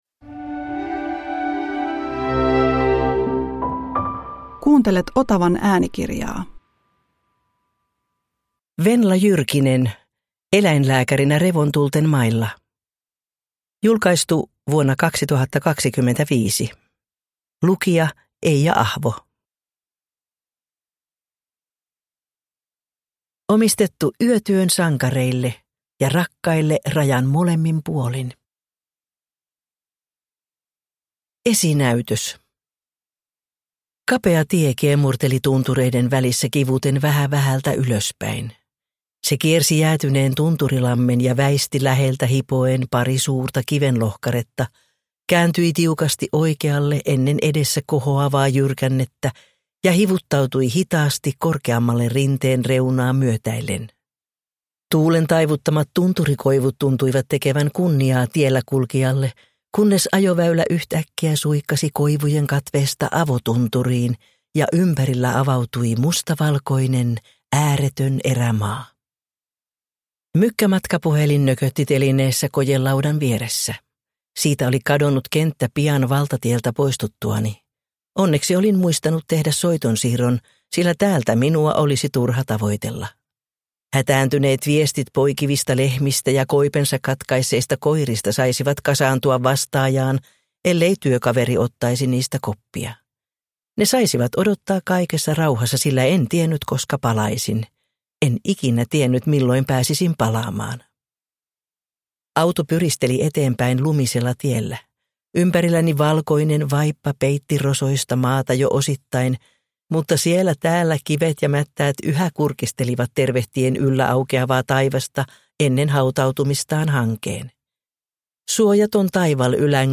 Eläinlääkärinä revontulten mailla – Ljudbok